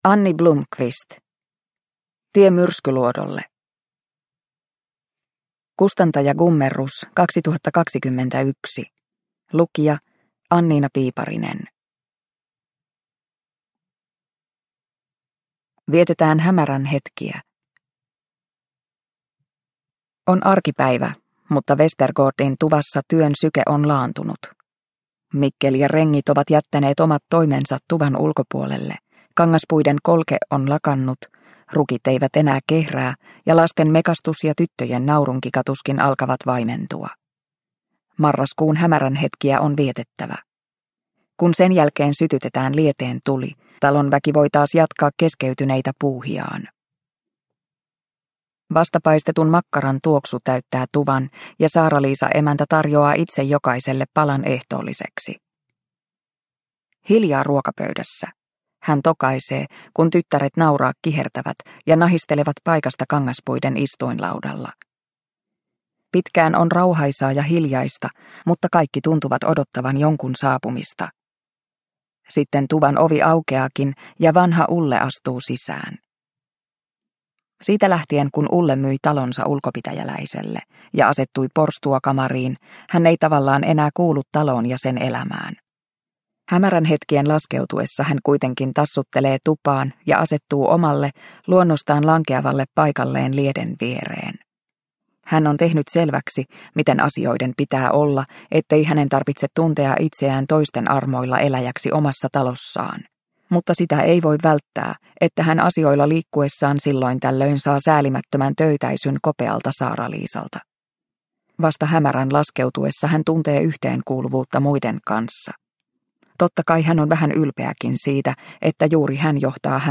Tie Myrskyluodolle – Ljudbok – Laddas ner